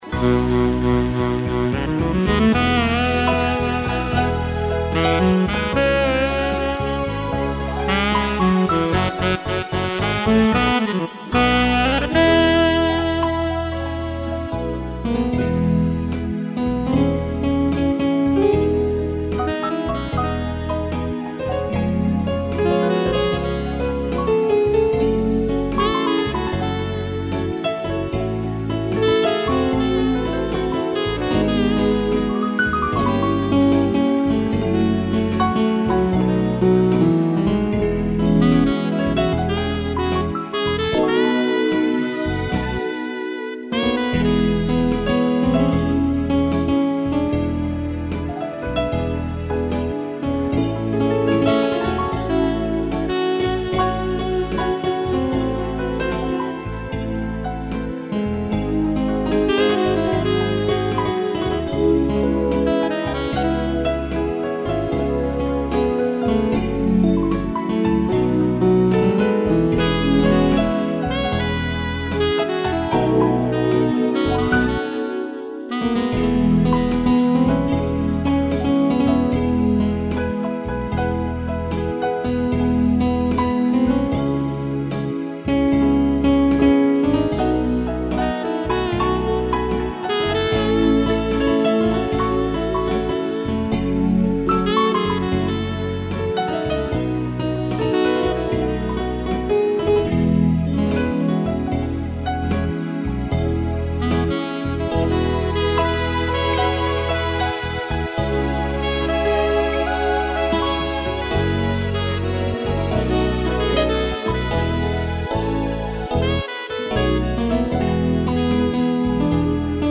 sax e piano
(instrumental)